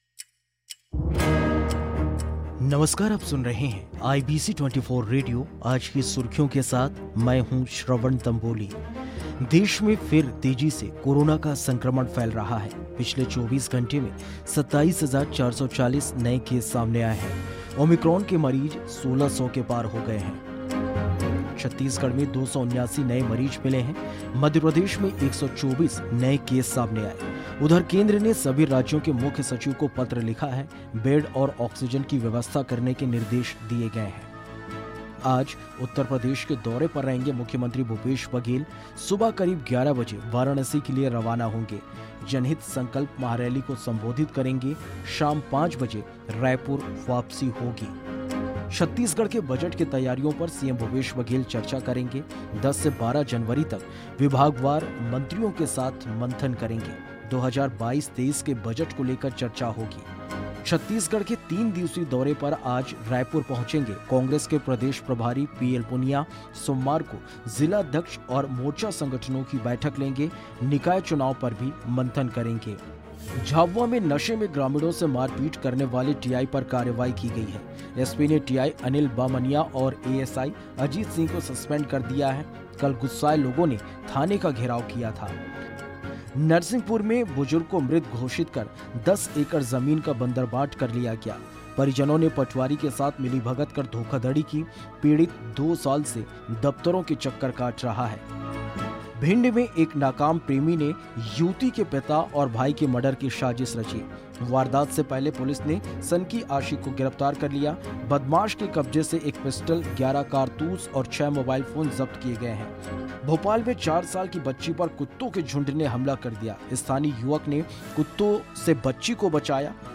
आज की सुर्खियां
Today's headlines